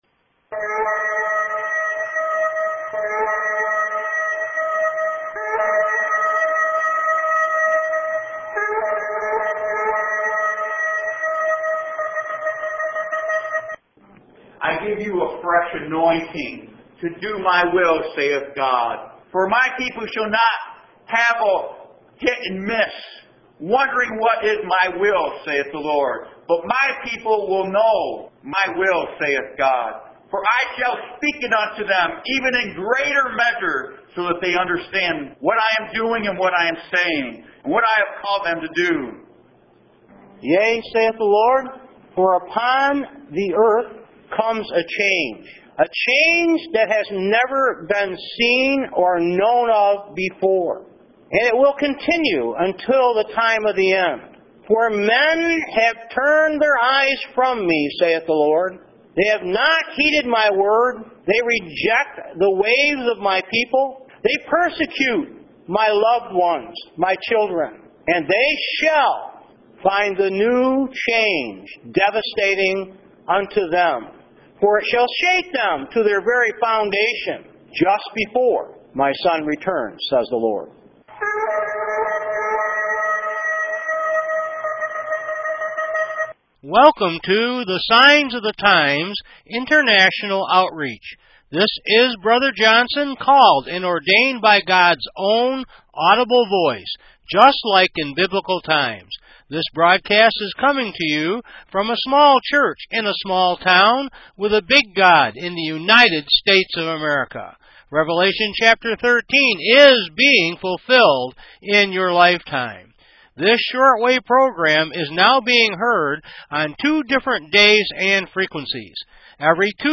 Message Details: Radio: 1/15/8 Gods News + Roundtable pt1 Last Days Revelations